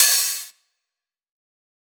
009_Lo-Fi Big Open Hat.wav